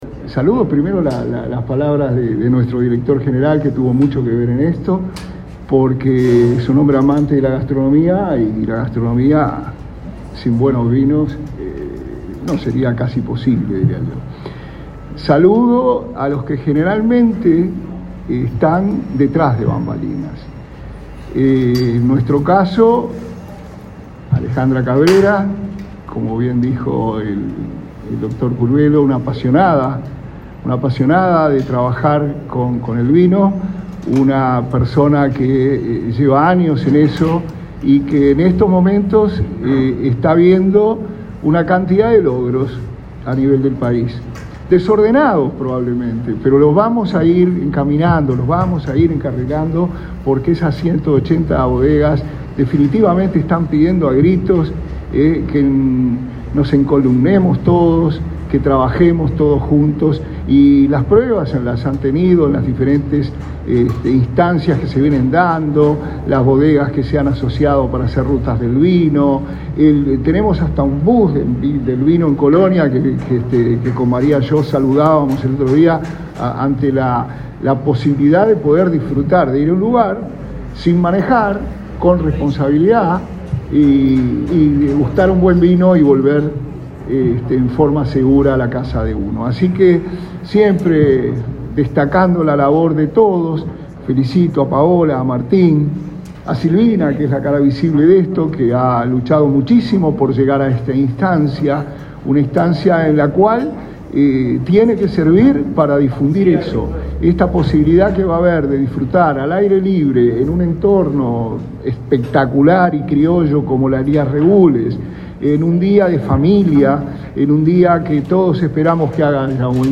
Palabras del ministro interino de Turismo, Remo Monzeglio
El ministro interino de Turismo, Remo Monzeglio, participó de la presentación del festival Uruwineday, que se realizará el próximo 31 de octubre en